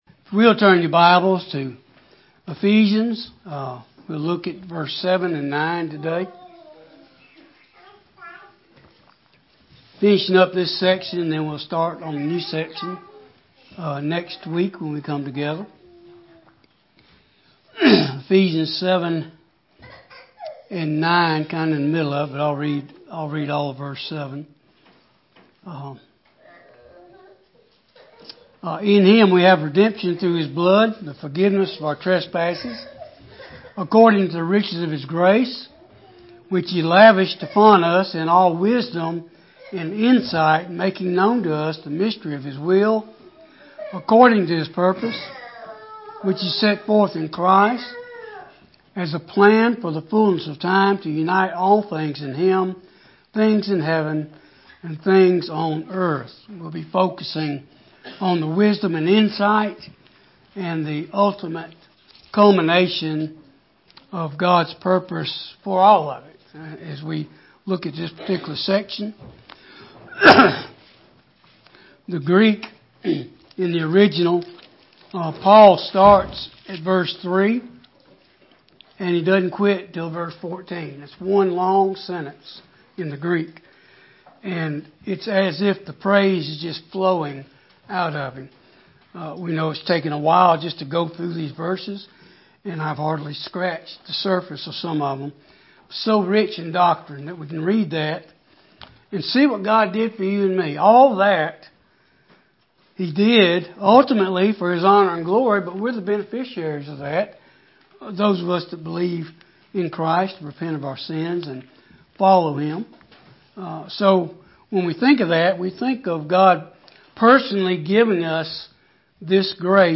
Gospel Preaching